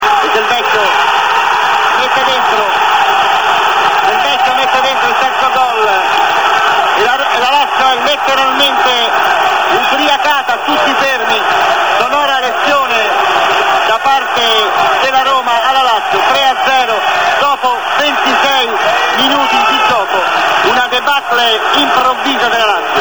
I gol della Roma commentati
da un radiocronista laziale!!!
Delvecchio3commentolazio.mp3